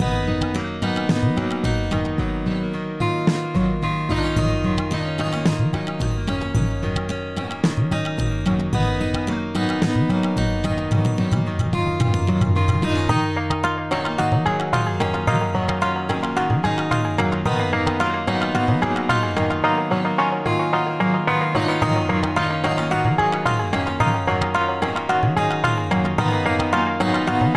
Featured in Tabla Ringtones